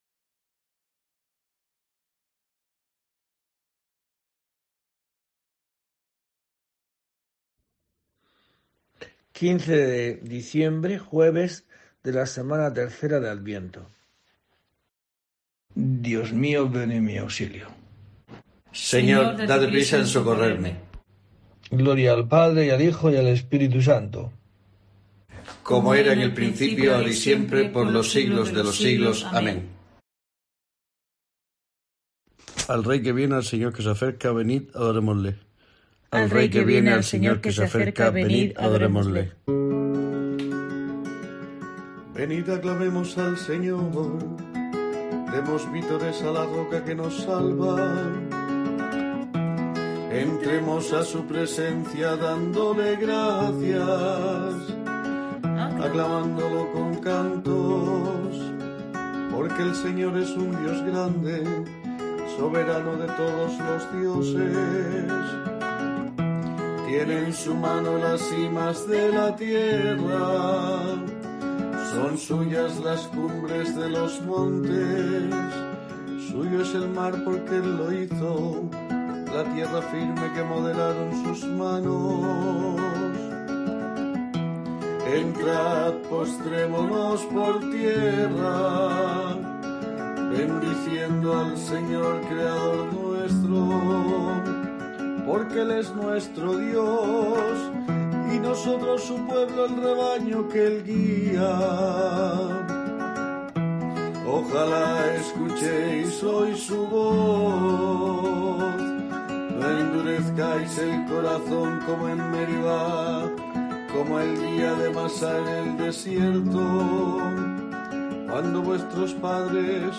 15 de diciembre: COPE te trae el rezo diario de los Laudes para acompañarte